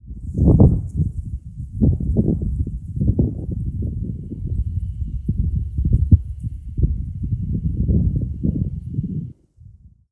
風,登山,山頂,暴風,強風,自然,452
効果音自然野外